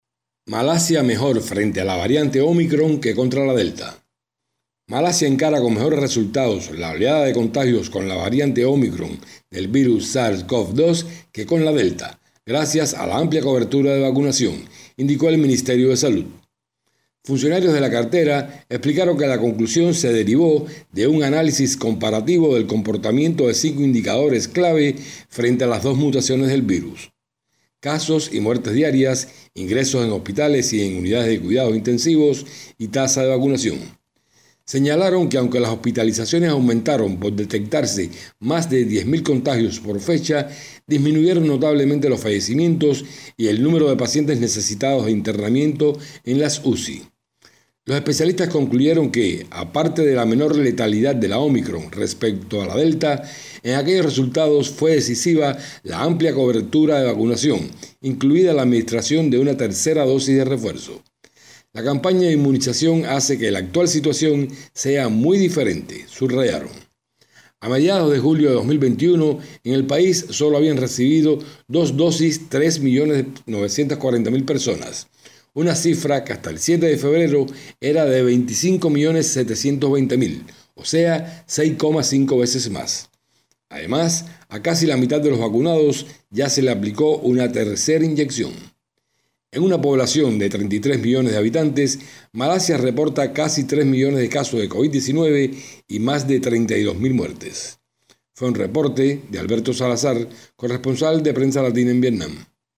desde Hanoi